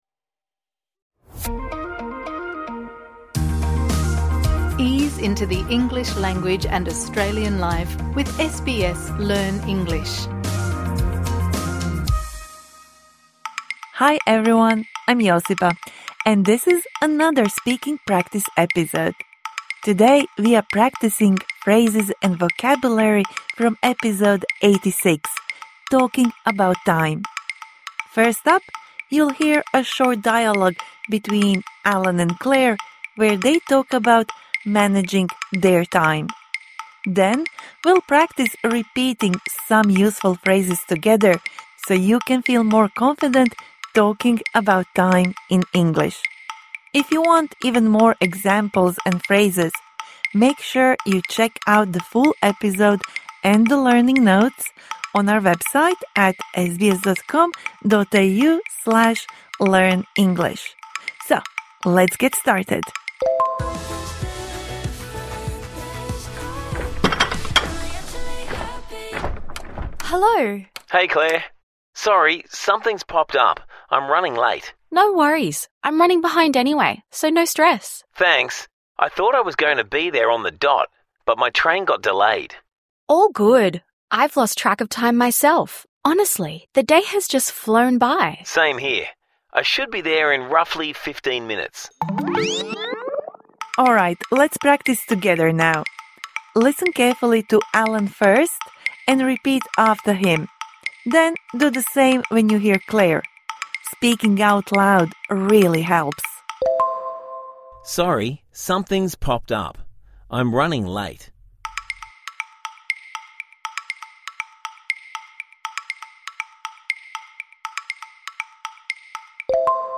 This bonus episode provides interactive speaking practice for the words and phrases you learnt in Episode #86 Talking about time (Med).